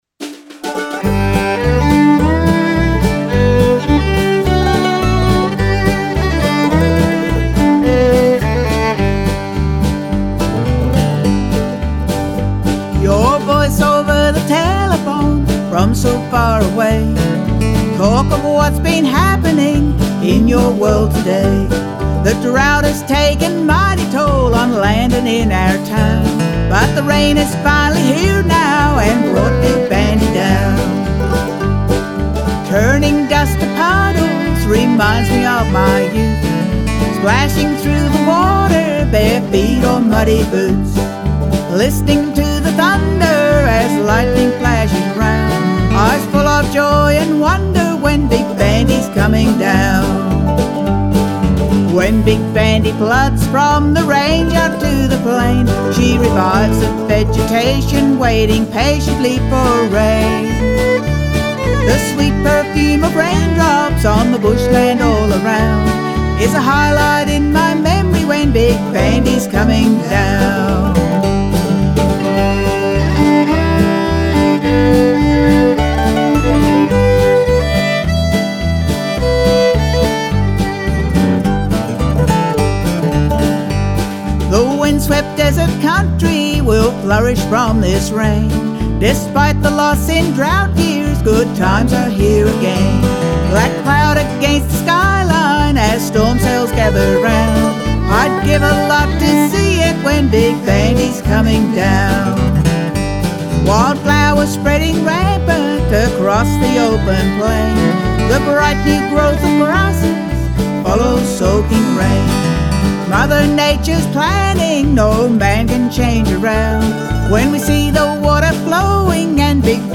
vibrant toe tapping bush story